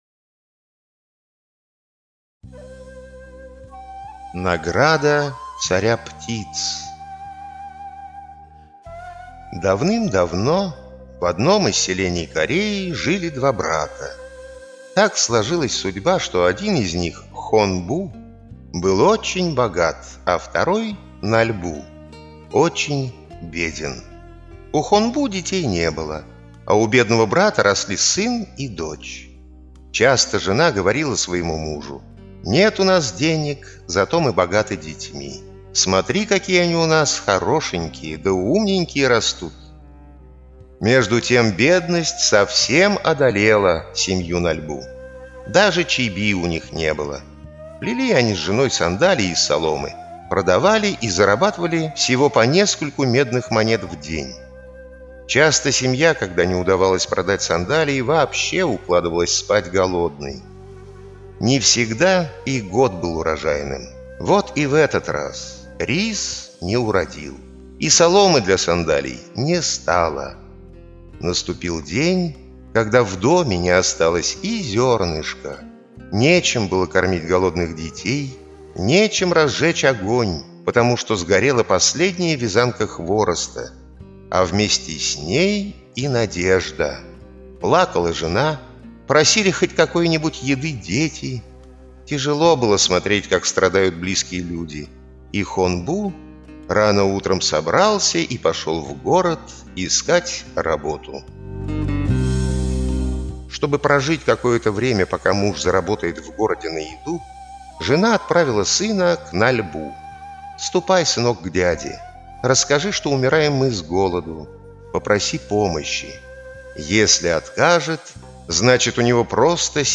Награда царя птиц - корейская аудиосказка - слушать скачать